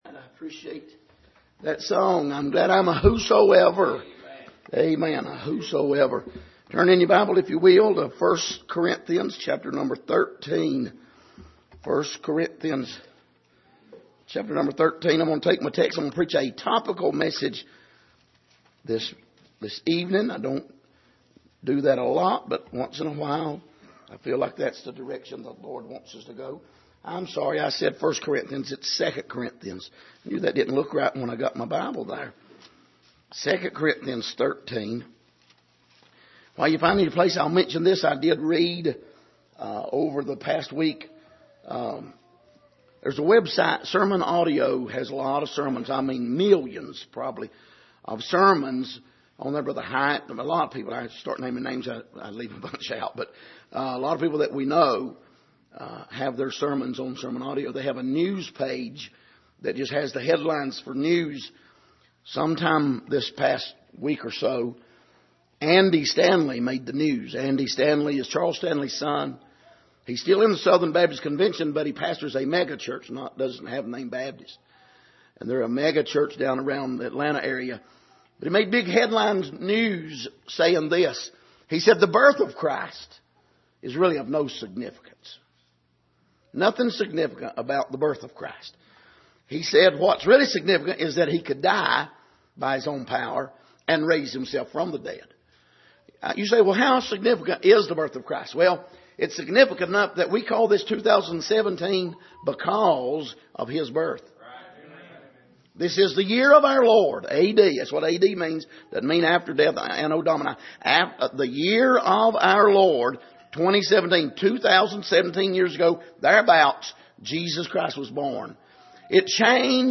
Passage: 2 Corinthians 13:5 Service: Sunday Evening